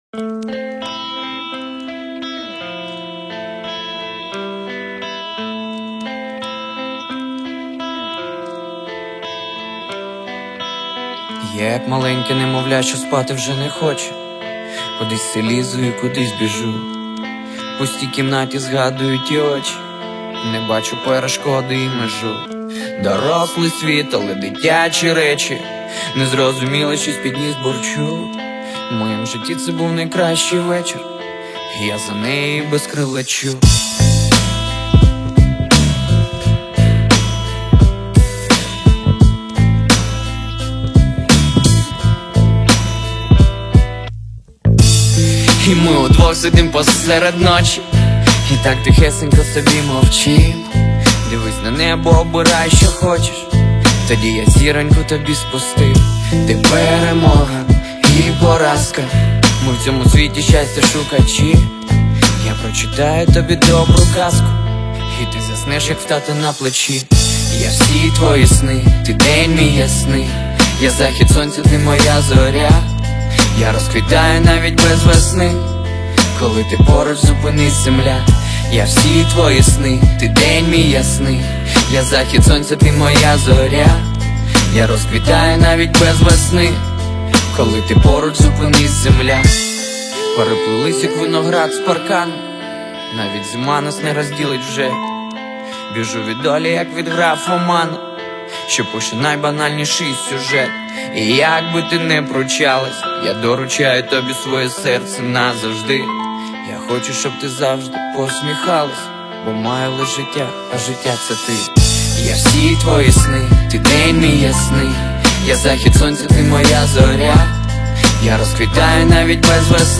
• Жанр: Реп / хіп-хоп